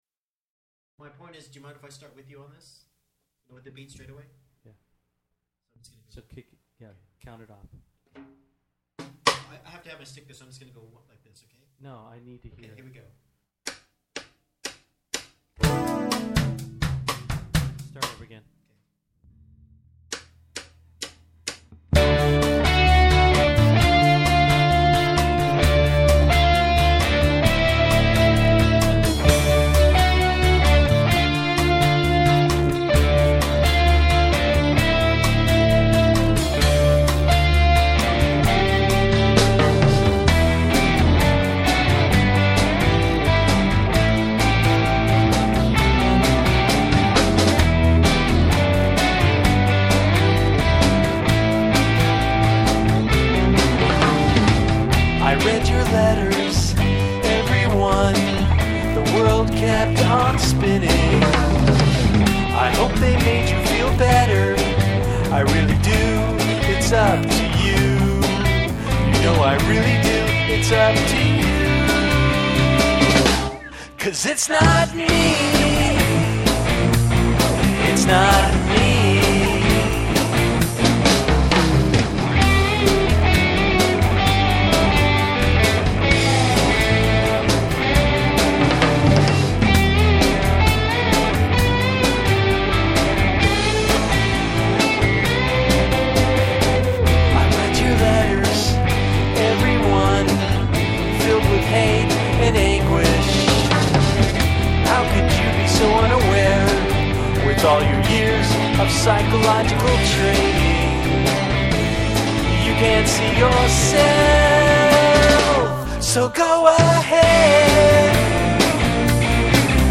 Drumloops recorded by real drummers on real drumkits.
131 bpm